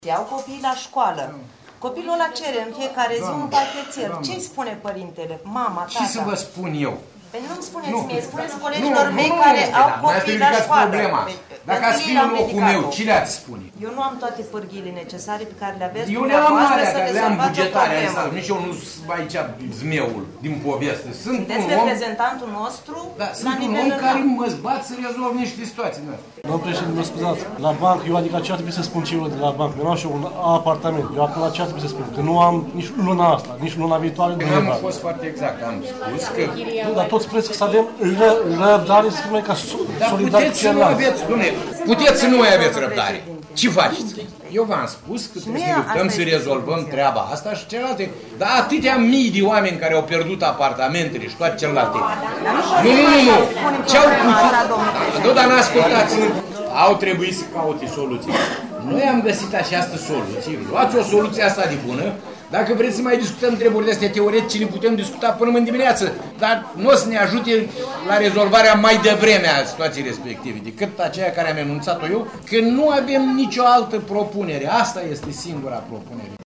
La sediul Direcţiei a venit preşedintele Consiliului Judeţean Vaslui, Dumitru Buzatu, care a discutat cu protestatarii pe marginea revendicărilor lor.
10-nov-rdj-17-protestatari-Buzatu.mp3